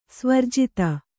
♪ svarjita